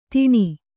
♪ tiṇi